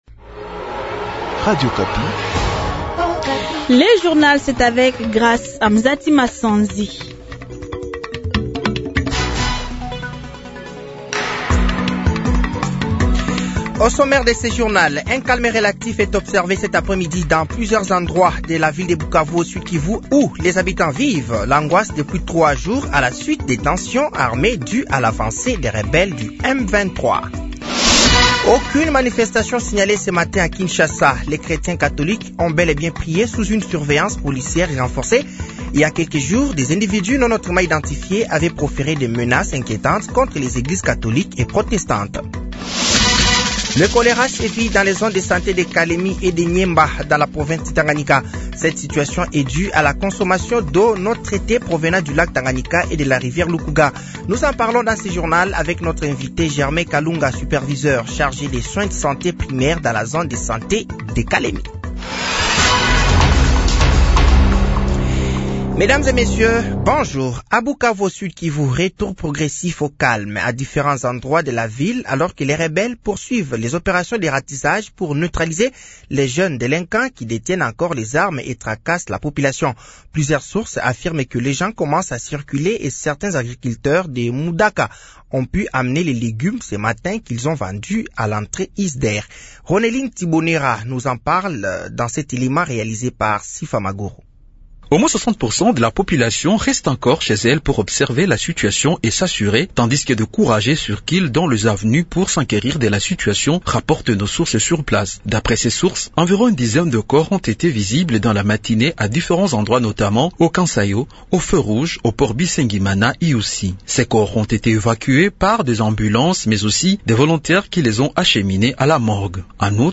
Journal français de 15h de ce dimanche 16 février 2025